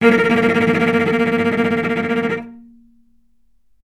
vc_trm-A#3-mf.aif